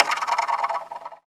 Index of /90_sSampleCDs/Optical Media International - Sonic Images Library/SI2_SI FX Vol 5/SI2_Gated FX 5
SI2 REIBHOLZ.wav